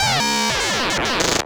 Glitch FX 26.wav